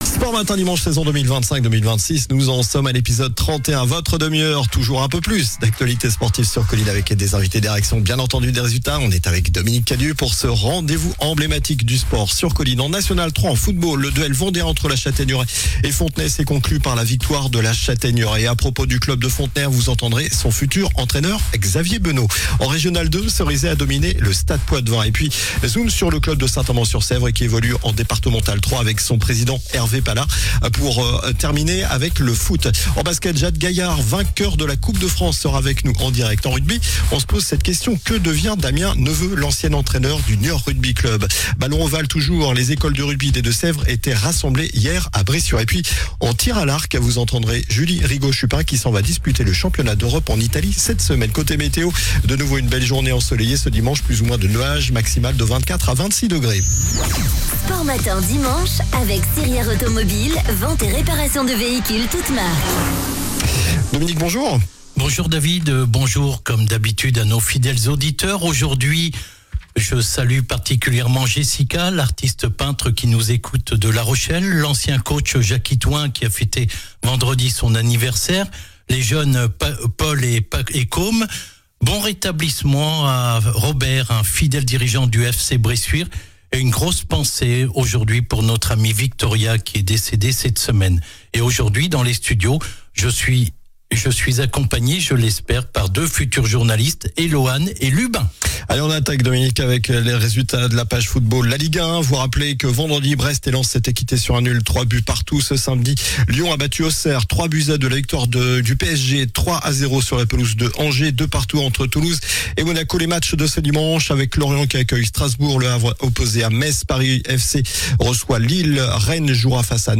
sports résultats invités réactions